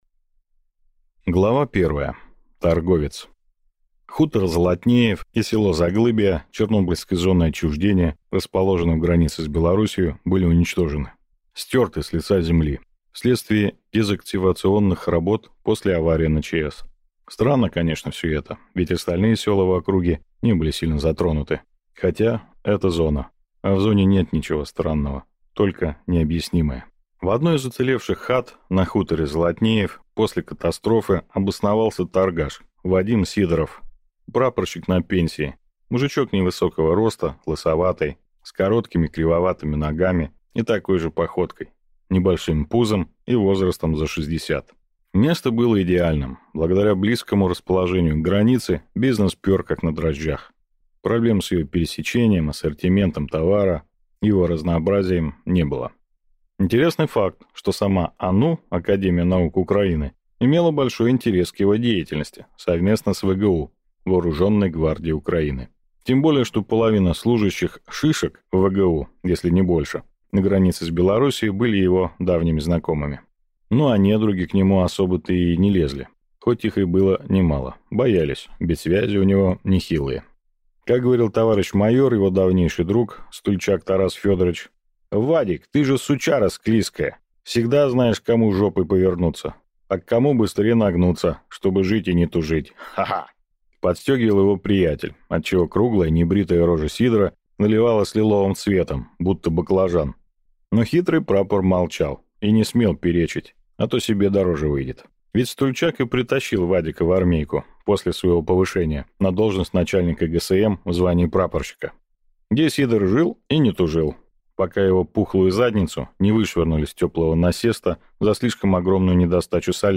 Аудиокнига Сталкер. Истории. Ч.З.О. Артефакт в пустоту | Библиотека аудиокниг